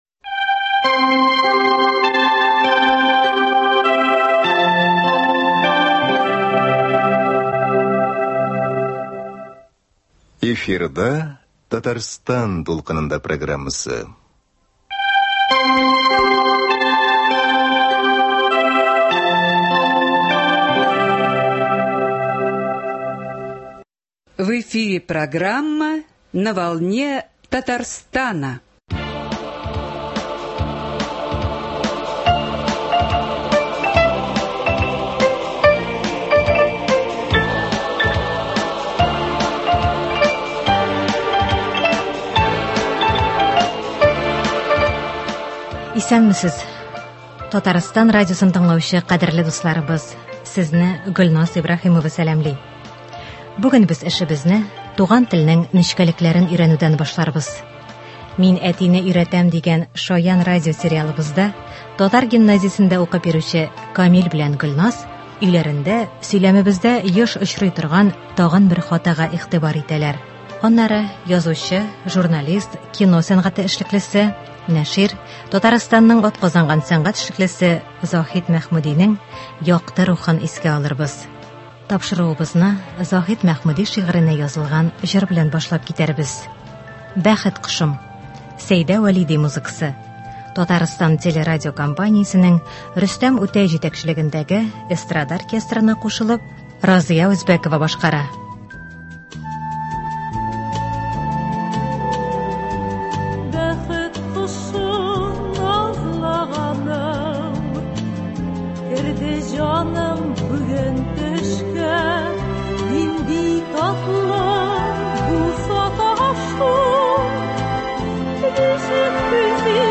Бүген без эшебезне туган телнең нечкәлекләрен өйрәнүдән башларбыз. “Мин әтине өйрәтәм” дигән шаян радиосериалыбызда татар гимназиясендә укып йөрүче Камил белән Гөлназ өйләрендә сөйләмебездә еш очрый торган тагын бер хатага игътибар итәләр.